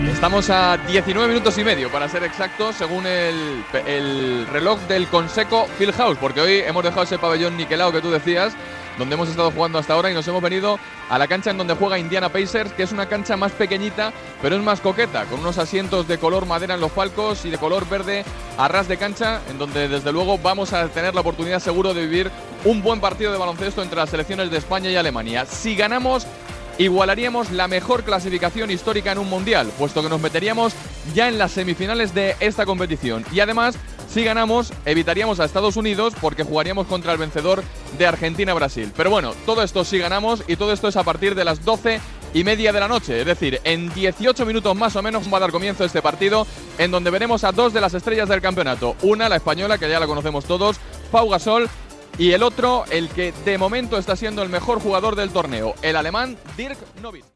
Esportiu
Programa presentat per Juan Ramón de la Morena.